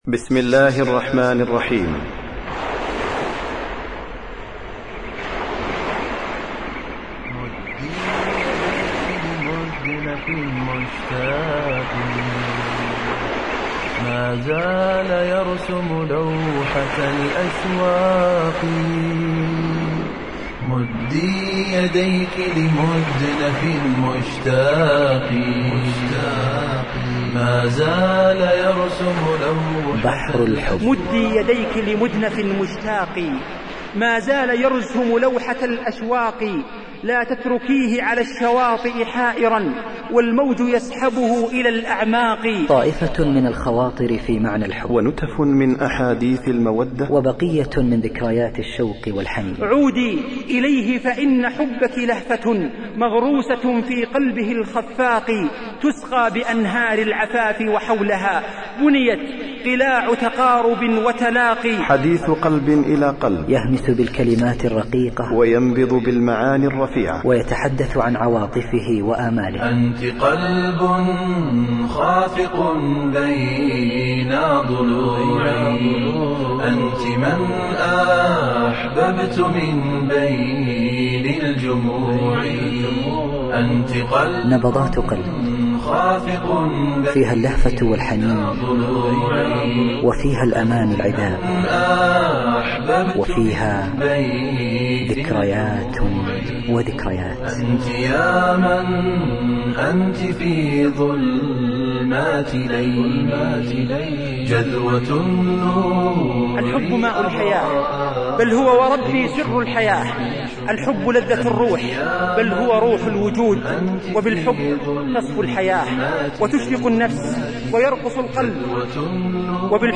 محاضراة